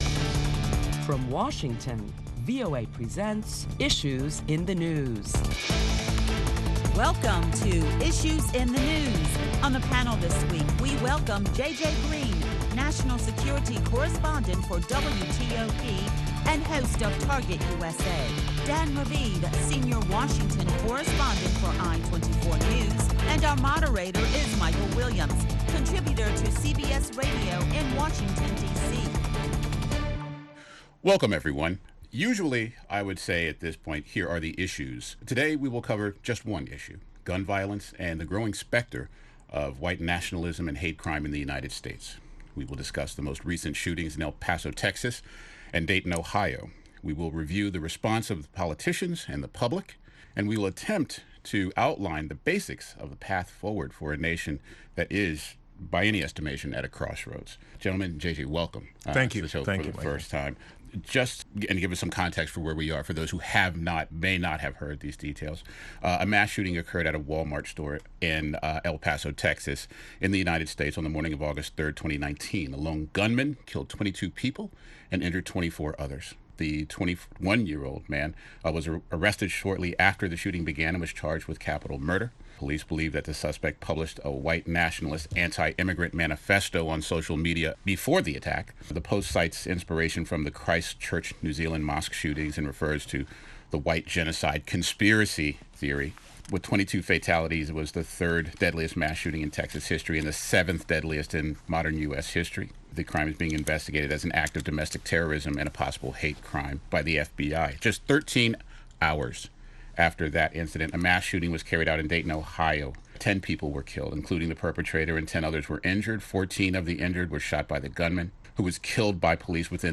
Listen to a panel of prominent Washington journalists as they deliberate the various reactions to the latest U.S. shootings and a look at what’s ahead with gun control.